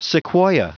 Prononciation du mot sequoia en anglais (fichier audio)
Prononciation du mot : sequoia
sequoia.wav